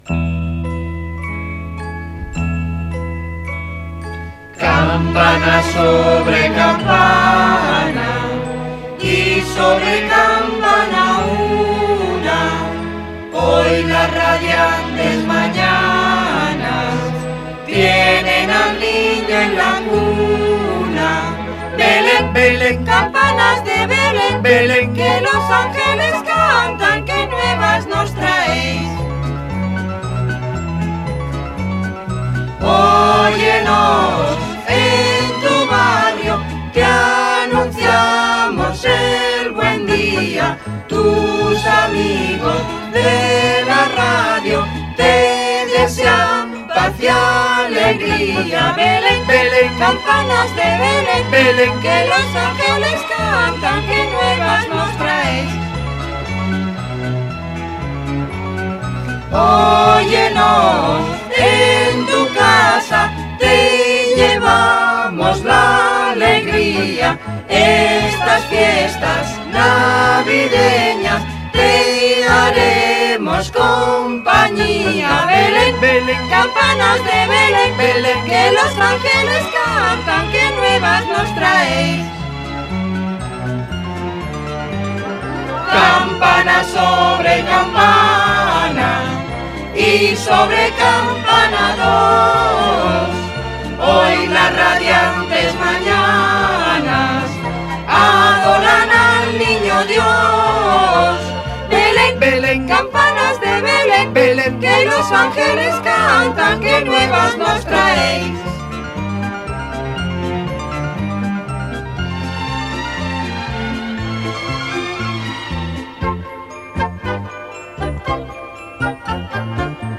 Nadala del programa
Entreteniment